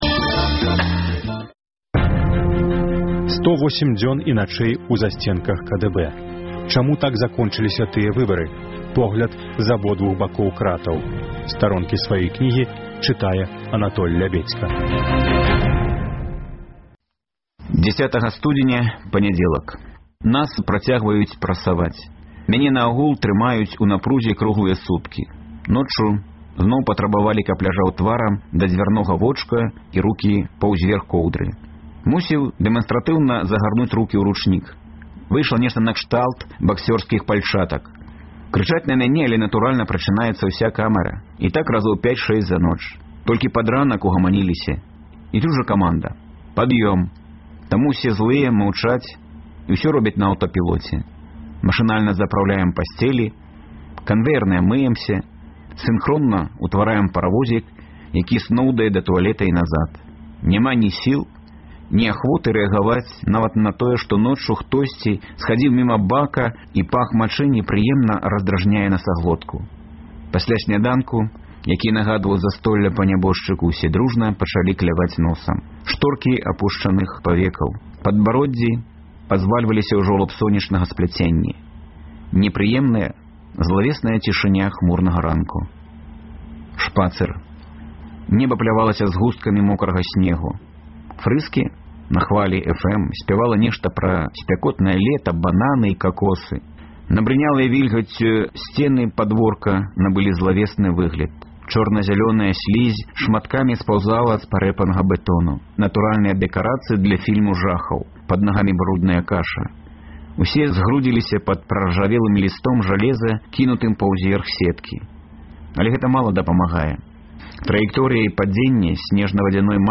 На хвалях Радыё Свабода гучаць разьдзелы кнігі Анатоля Лябедзькі «108 дзён і начэй у засьценках КДБ» у аўтарскім чытаньні.